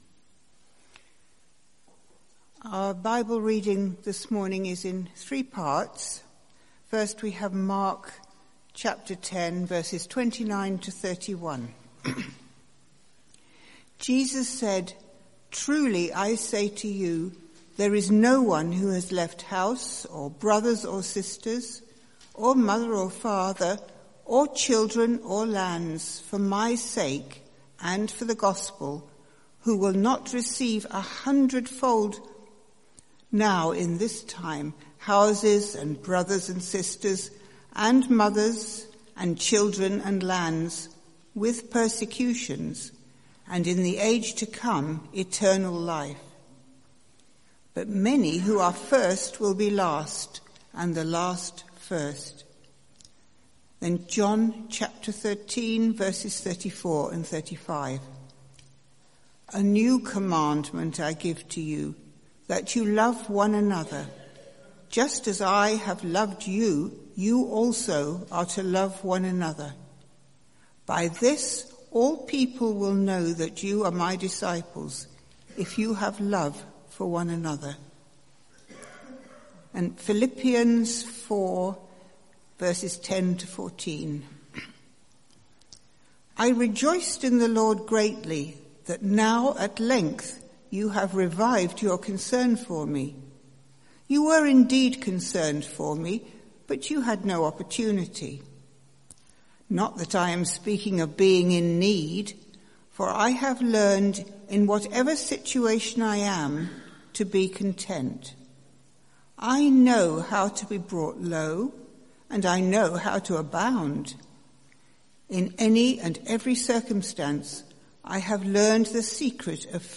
Sermon Series: Marriage & Singleness | Sermon Title: Singleness 2